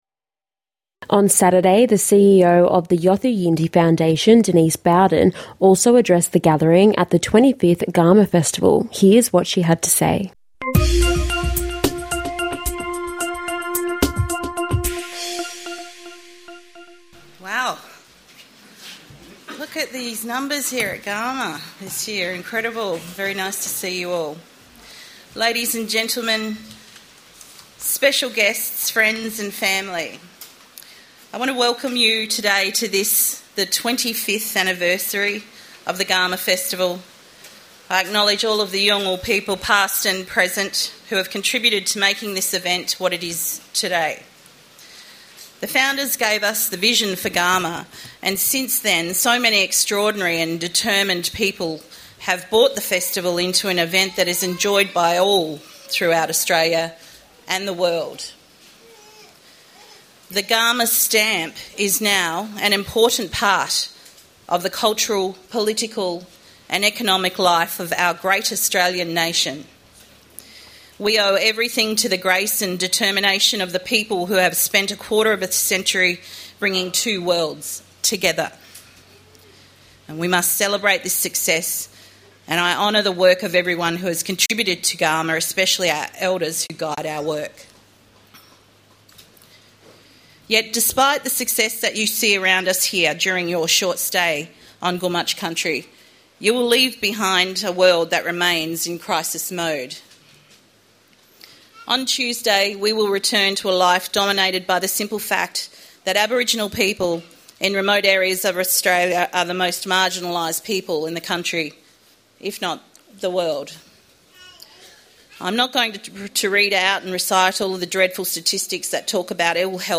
NITV Radio brings you highlights from this years 2025 Garma Festival.